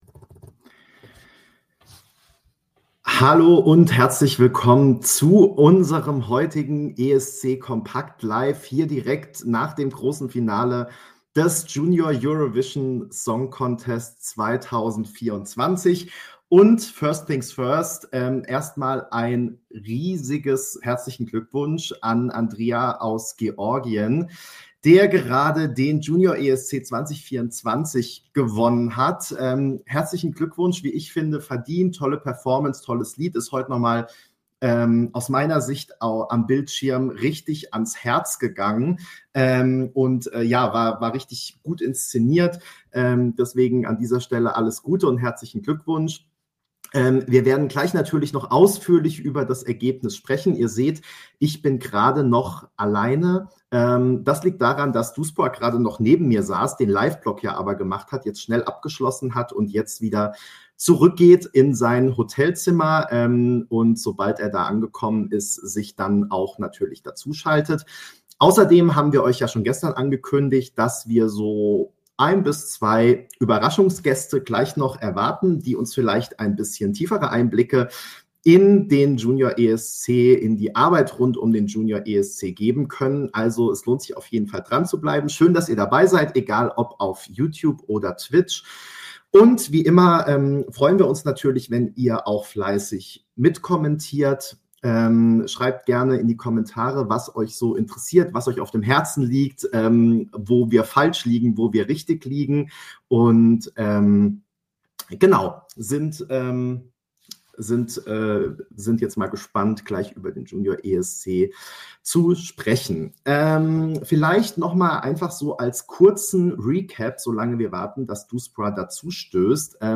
ESC kompakt LIVE